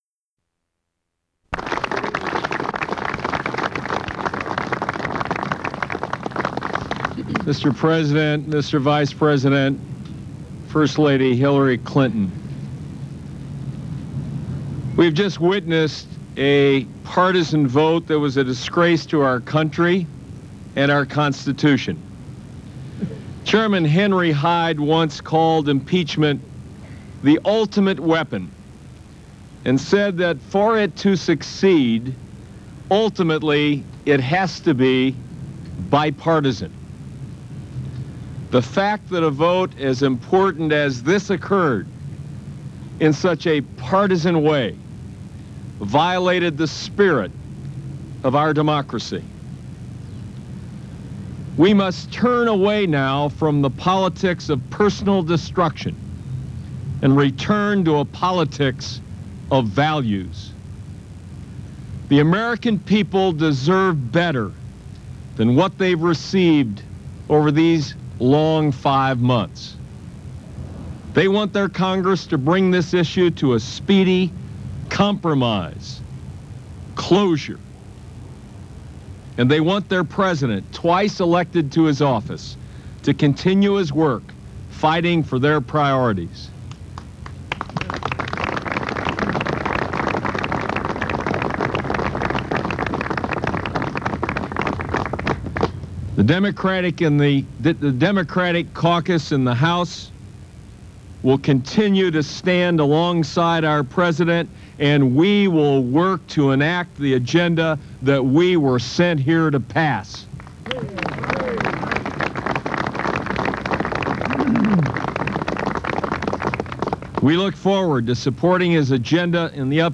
House Minority Leader Dick Gephardt addresses Democratic House members in a Rose Garden session, following the vote to impeach President Clinton. Gephardt is introduced by Vice President Al Gore.
Broadcast on CNN-TV, News, December 19, 1998.